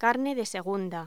Locución: Carne de segunda